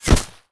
bullet_impact_grass_04.wav